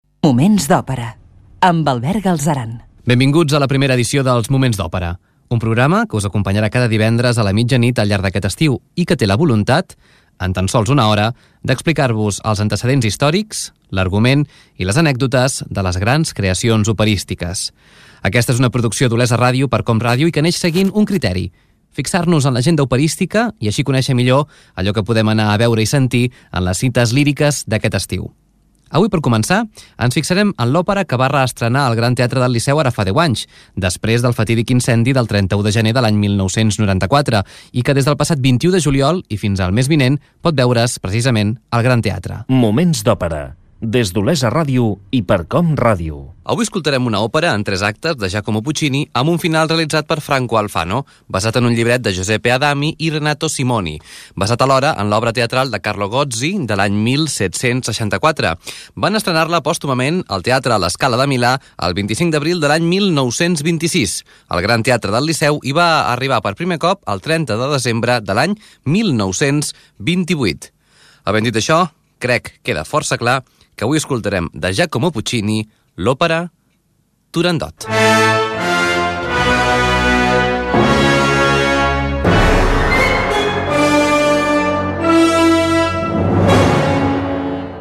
Identificació del programa, presentació i comentari sobre l'òpera "Turandot", de Puccini
Musical